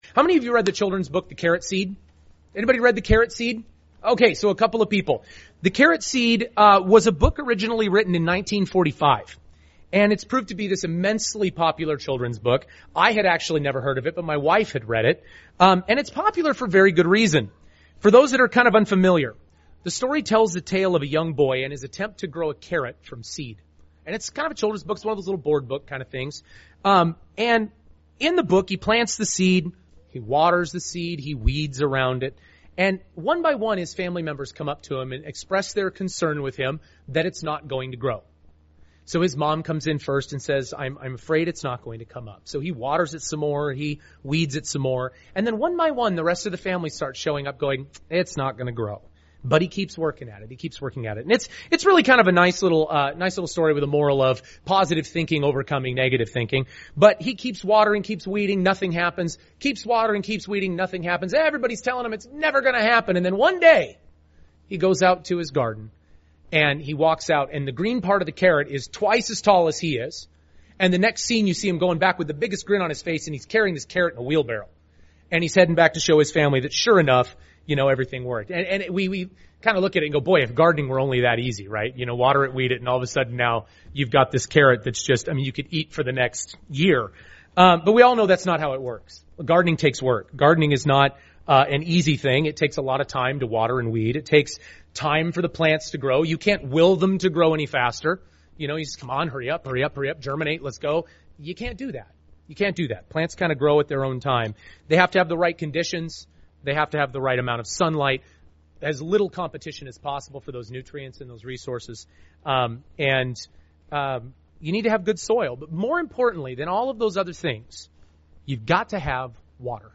Regular watering UCG Sermon Transcript This transcript was generated by AI and may contain errors.